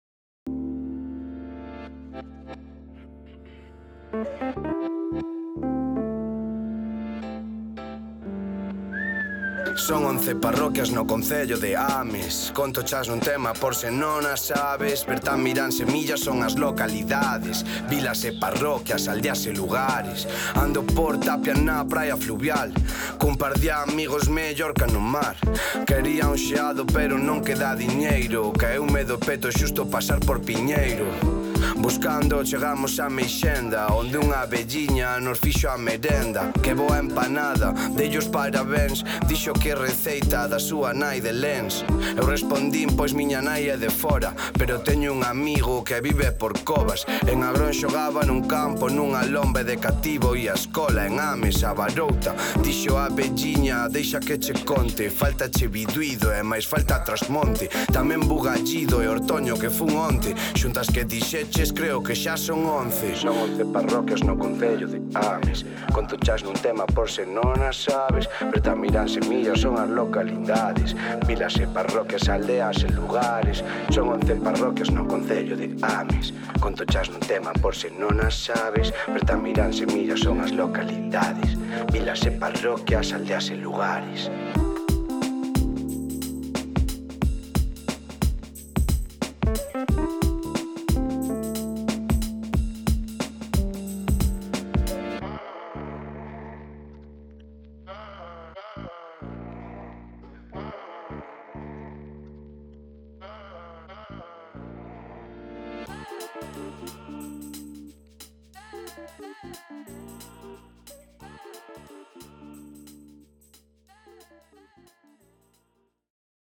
Lembrades ese rap tan chulo?